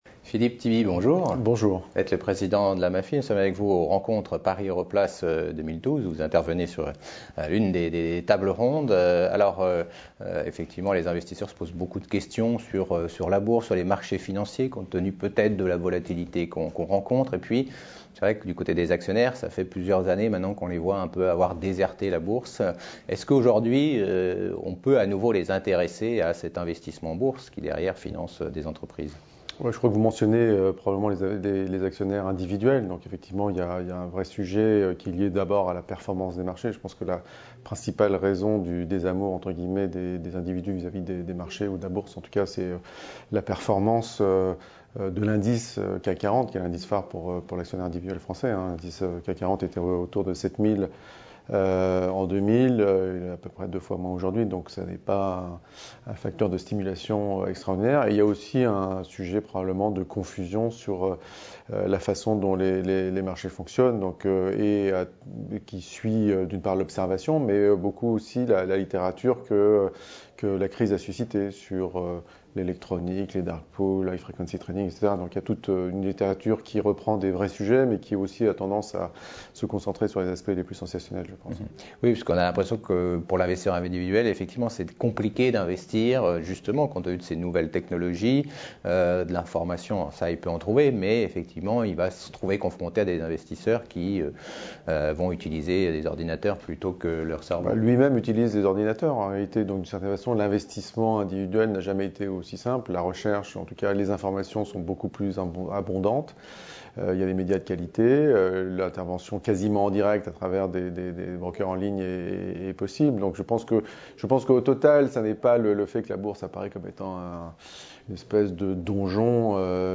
Rencontres Paris Europlace 2012 : Investisseurs et Bourse, la confiance à retrouver